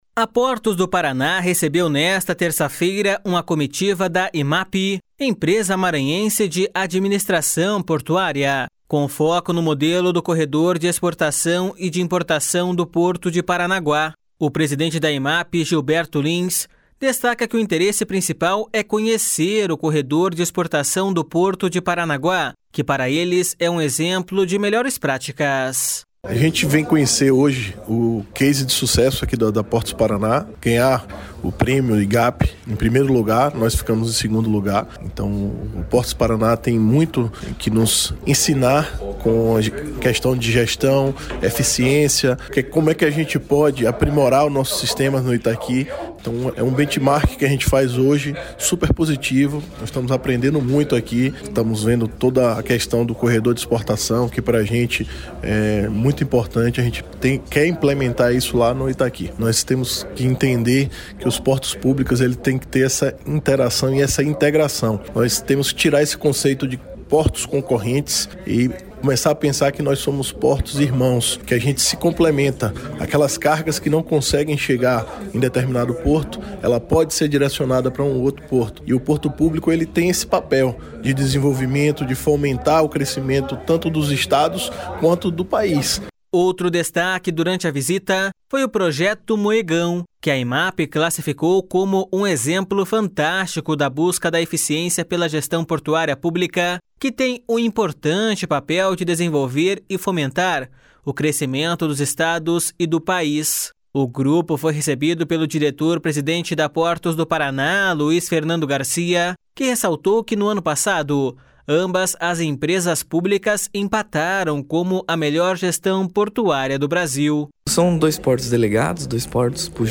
A Portos do Paraná recebeu nesta terça-feira uma comitiva da Emap, Empresa Maranhense de Administração Portuária, com foco no modelo do corredor de exportação e de importação do Porto de Paranaguá. O presidente da Emap, Gilberto Lins, destaca que o interesse principal é conhecer o Corredor de Exportação do Porto de Paranaguá, que para eles é um exemplo de melhores práticas.// SONORA GILBERTO LINS.//
O grupo foi recebido pelo diretor-presidente da Portos do Paraná, Luiz Fernando Garcia, que ressaltou que no ano passado ambas as empresas públicas empataram como a melhor gestão portuária do Brasil.// SONORA LUIZ FERNANDO GARCIA.//